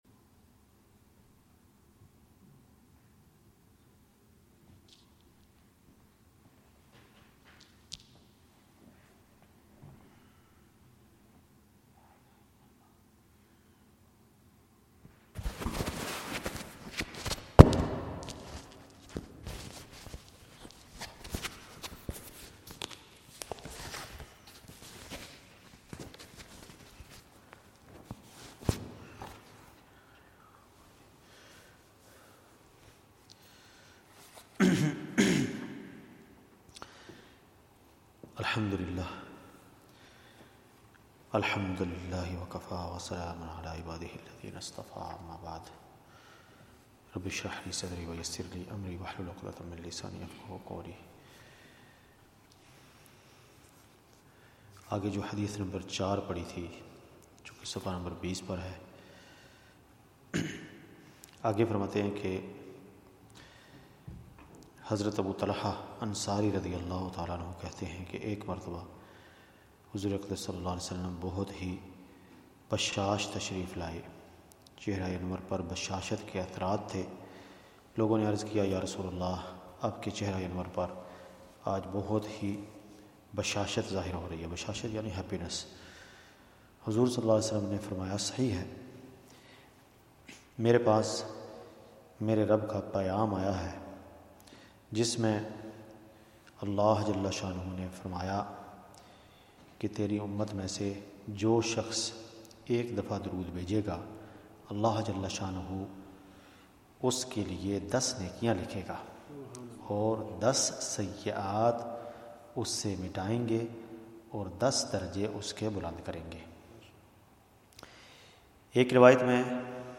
Friday night Drood sharif majlis